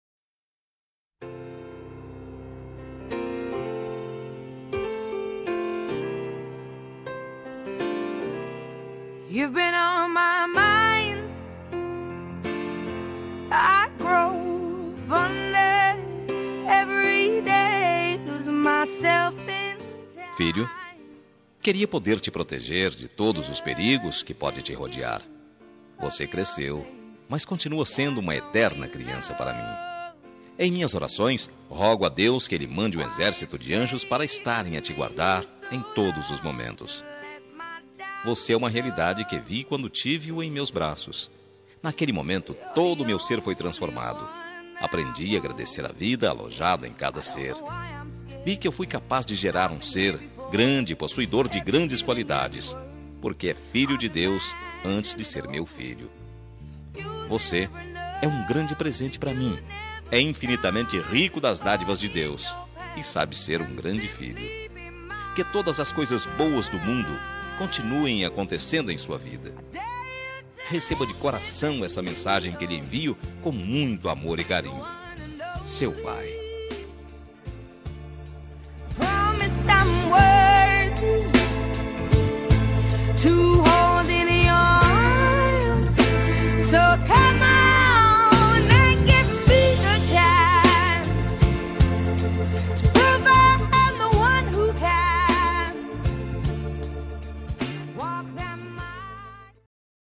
Homenagem para Filho – Voz Masculino – Cód: 8131